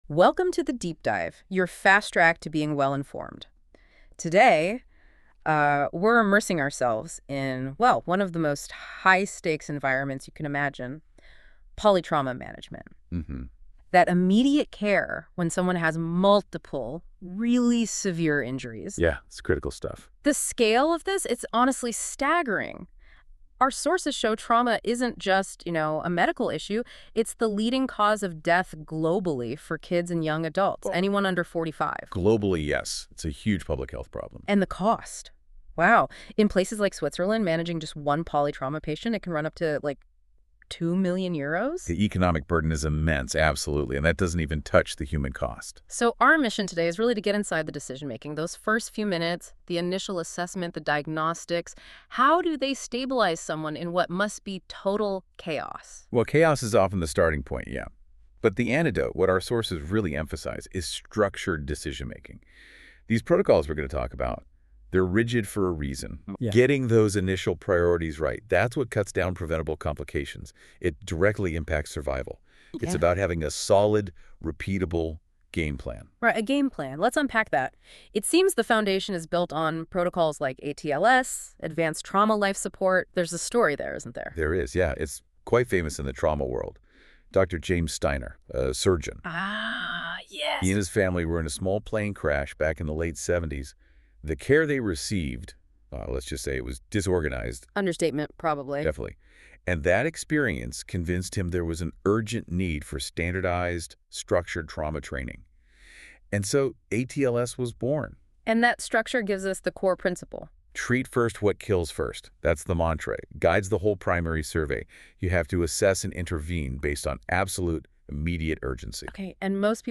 This essential episode covers the systematic approach to initial assessment in polytrauma patients. Our expert panel discusses the structured methodology for rapid yet comprehensive evaluation, ensuring no critical injuries are missed while prioritizing life-threatening conditions.